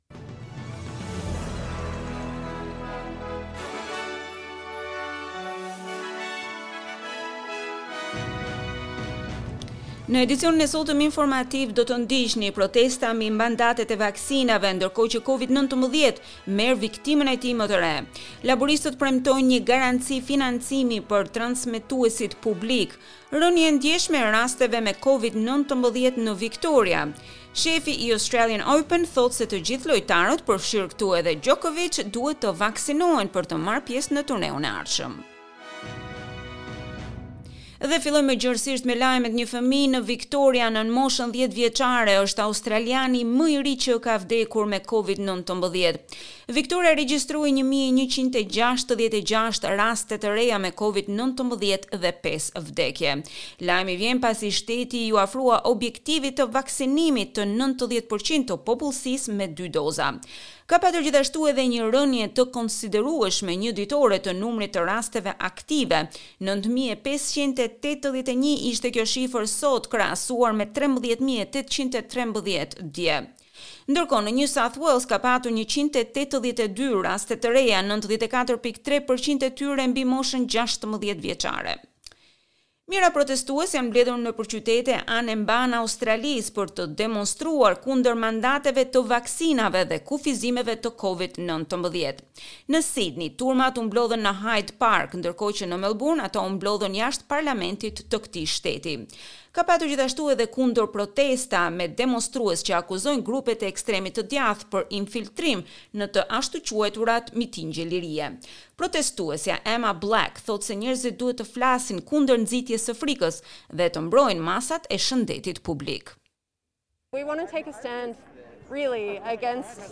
SBS News Bulletin in Albanian - 20 November 2021